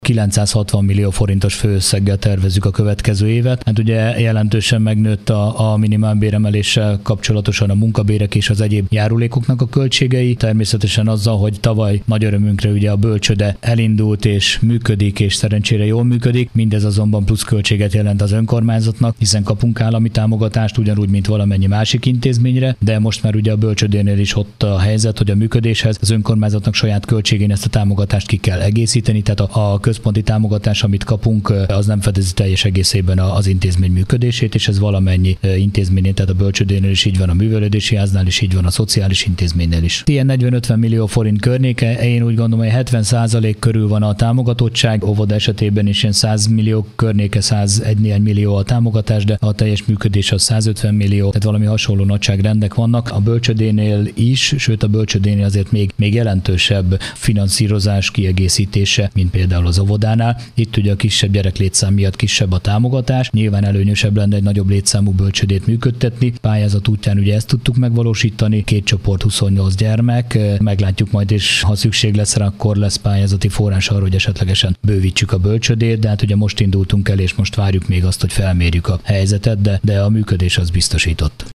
Hírek